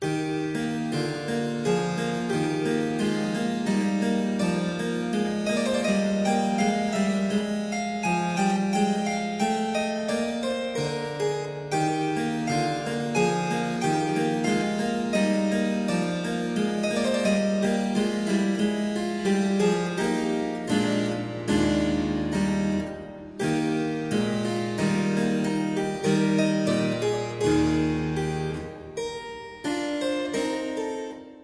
Andante un poco vivace
harpsichord